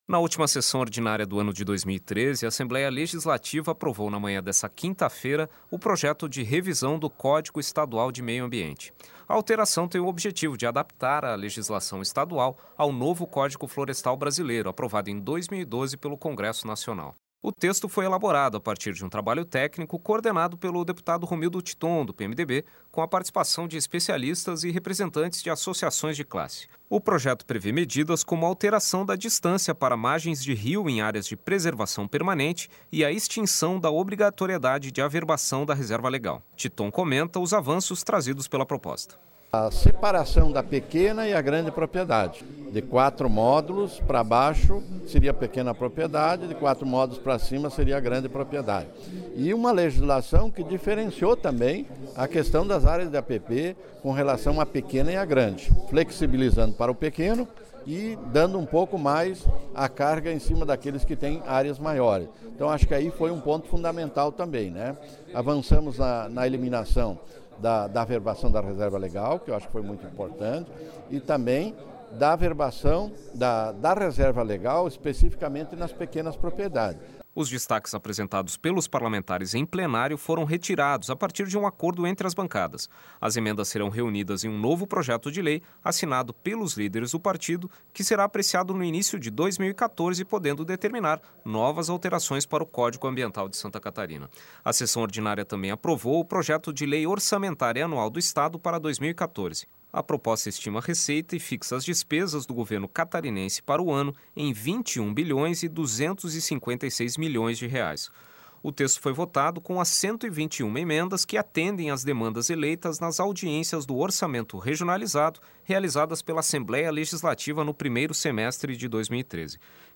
Entrevistas com: deputado Romildo Titon (PMDB) e deputado Gilmar Knaesel (PSDB).